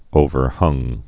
(ōvər-hŭng)